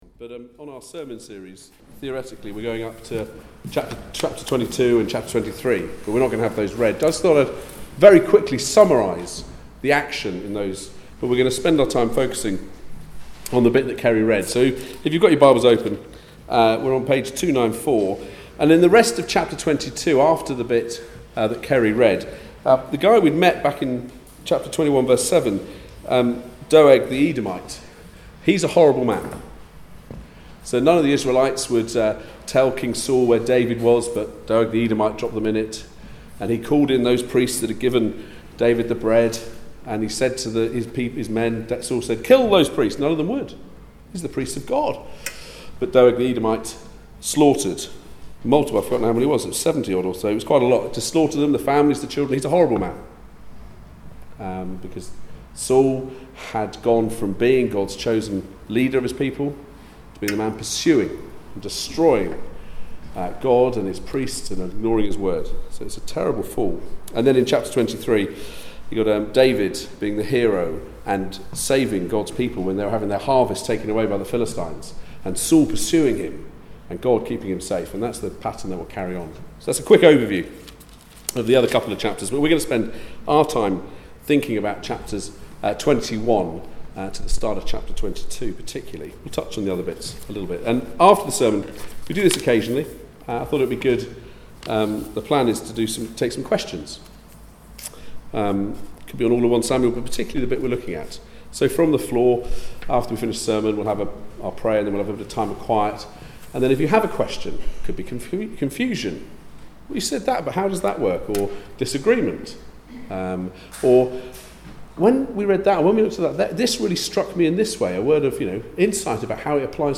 1 Samuel 21:1-22:5 Service Type: Weekly Service at 4pm Bible Text